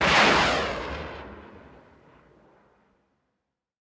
rocket-launch.mp3